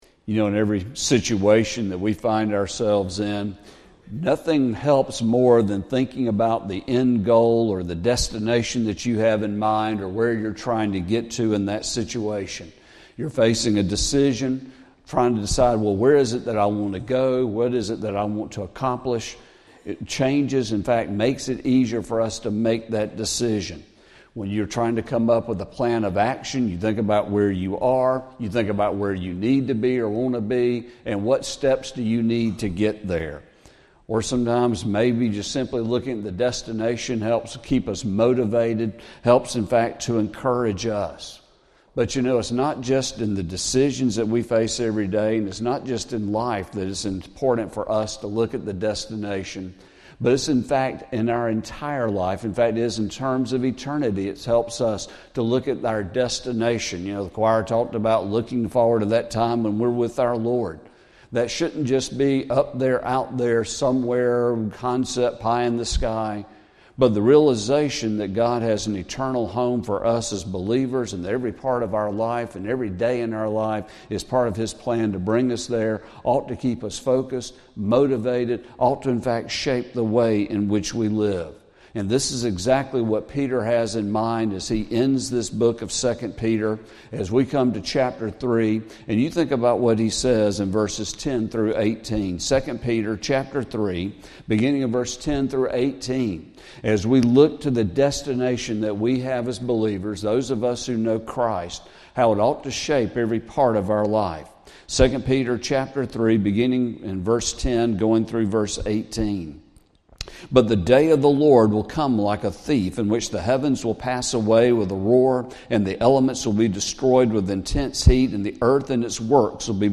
Sermon | March 23, 2025 – First Baptist Church of Ashburn